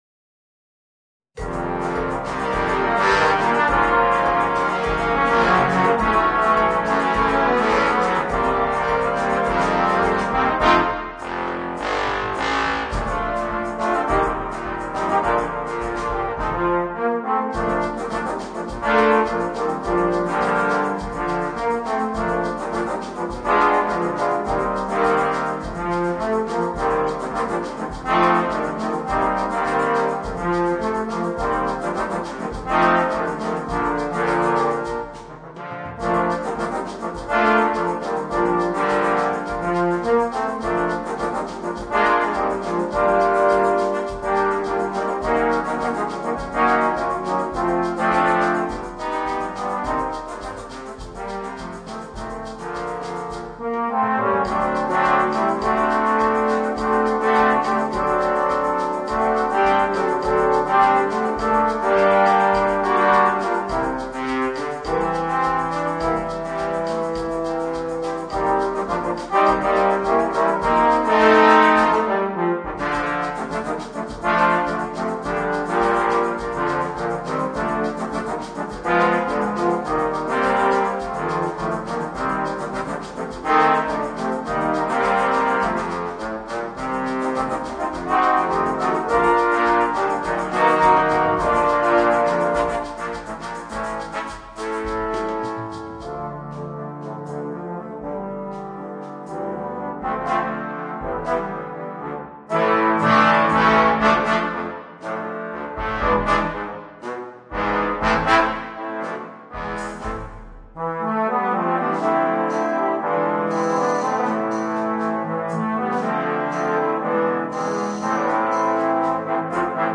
Voicing: 8 Trombones